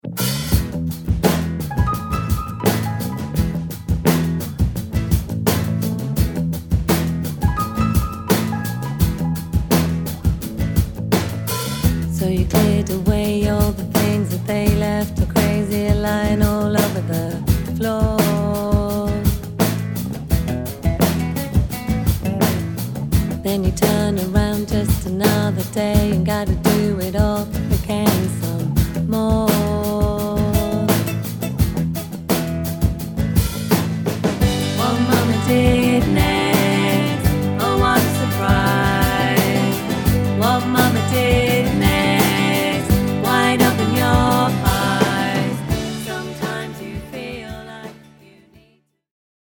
Rough demo tracks